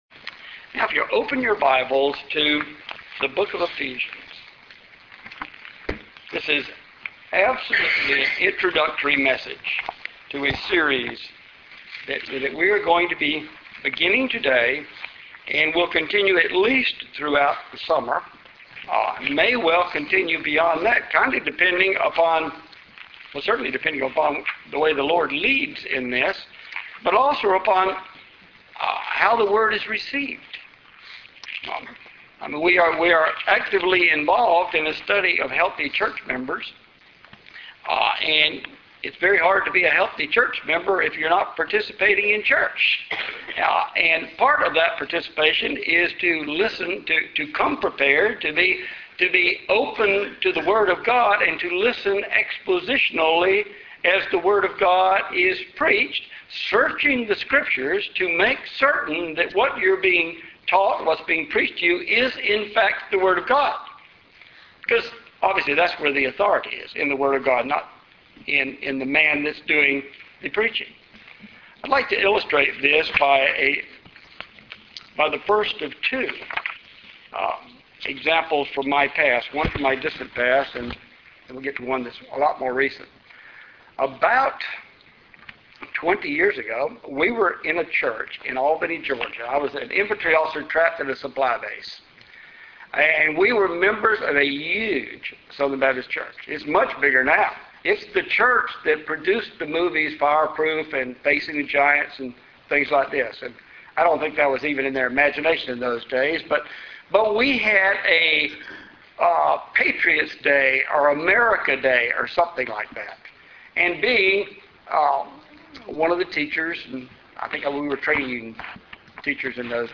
Passage: Ephesians 1:1-2 Service Type: Sunday Morning